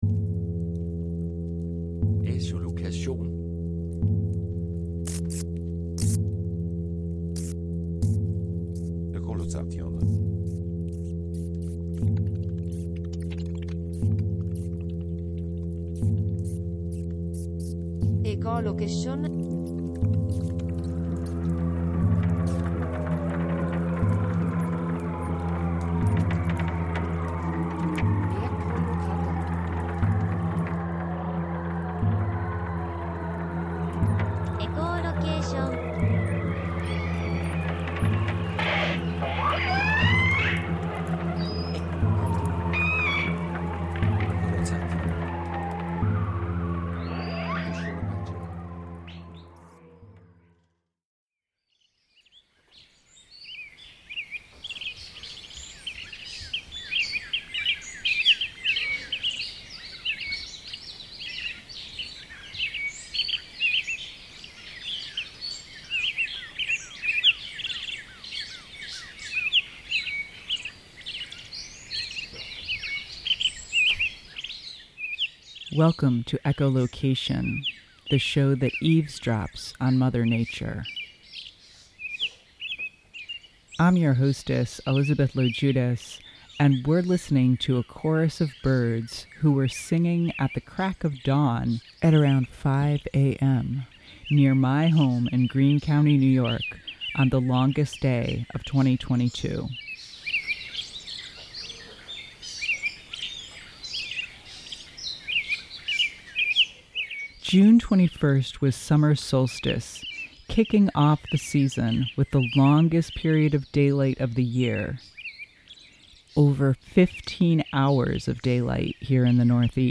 On this broadcast, we explore scientific research on the Hudson River that uses soundscape recordings and acoustic technologies such as side-scan sonar, a tool inspired by echolocation. The show features excerpts from a lecture